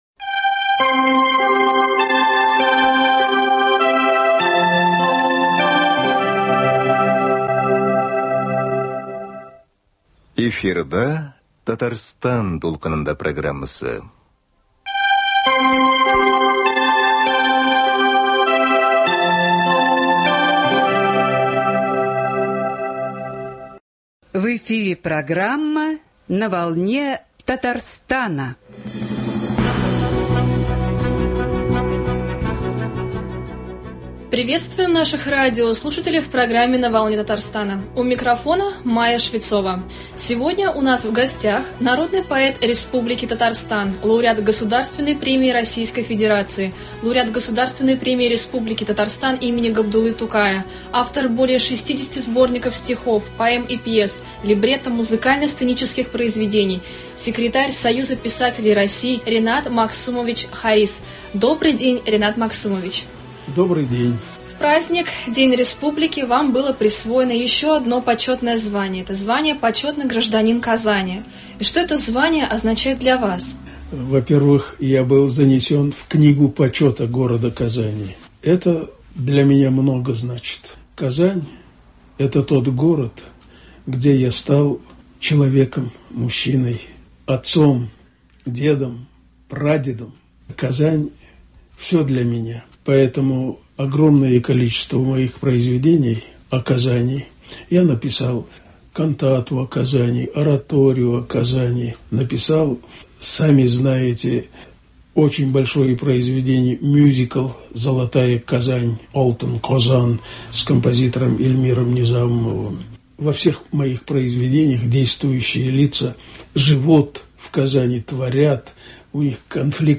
Гость программы Ренат Магсумович Харисов — народный поэт Республики Татарстан, лауреат Государственной премии Российской Федерации, лауреат Государственной премии Республики Татарстан имени Габдуллы Тукая, автор более 60 сборников стихов, поэм и пьес.